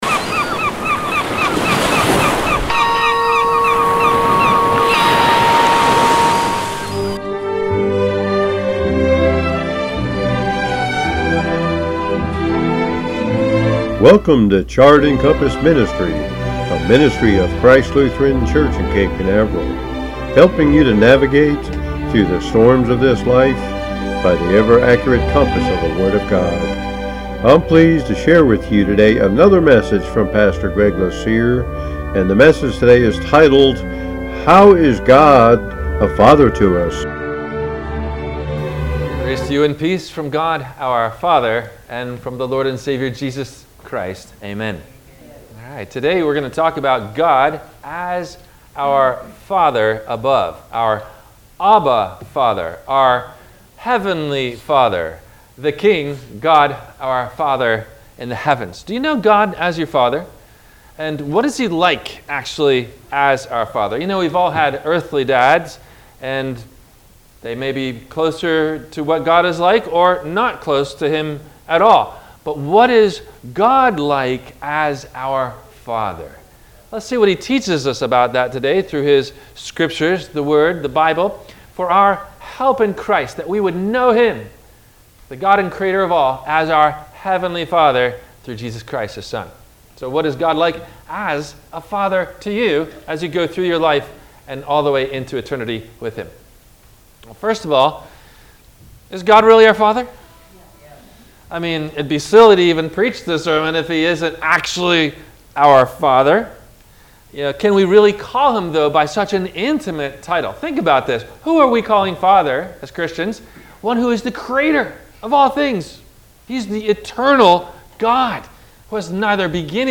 How Is God A Father To Us? – WMIE Radio Sermon – July 07 2025